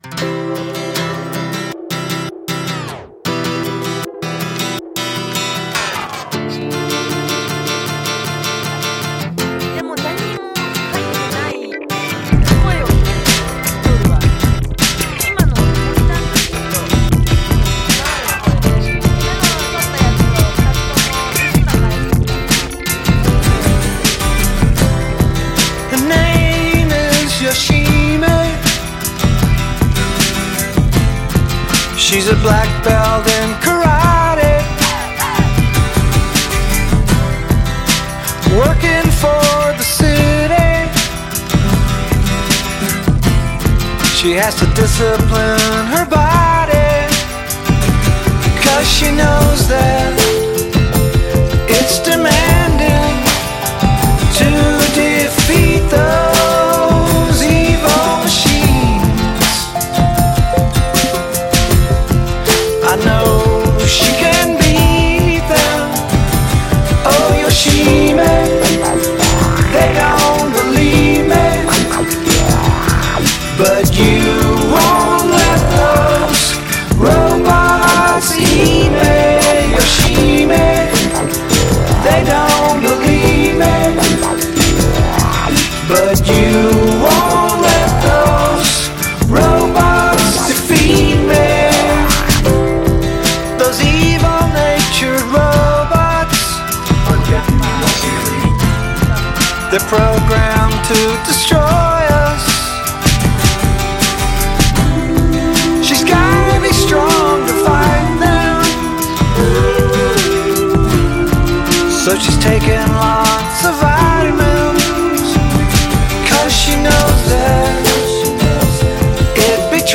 pseudo-novelty